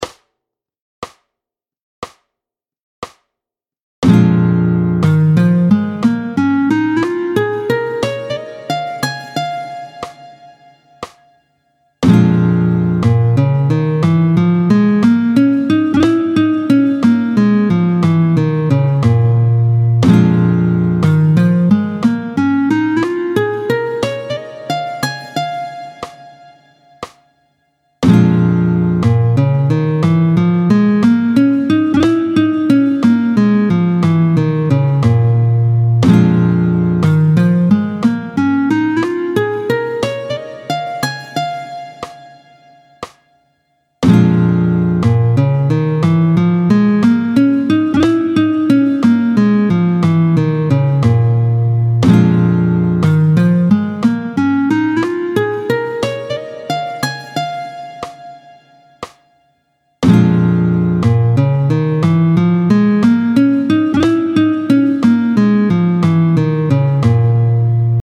31-06 La « blue note », tempo 60